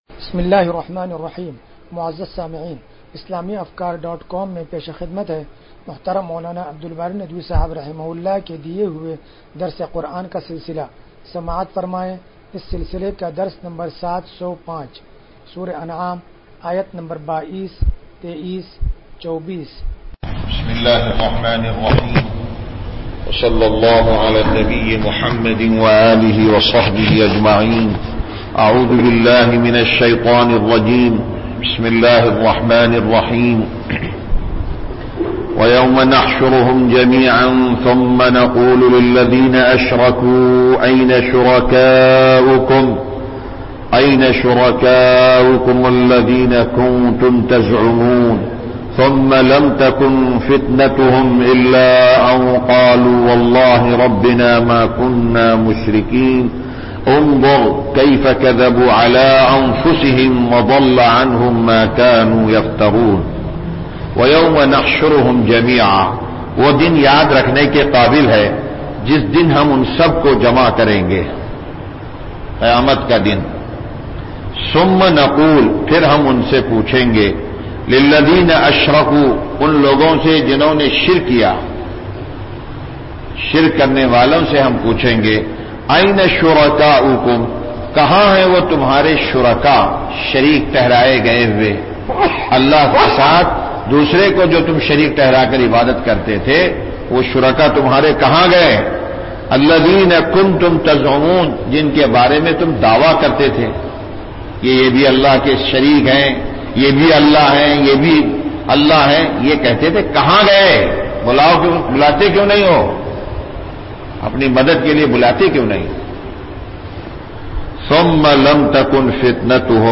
درس قرآن نمبر 0705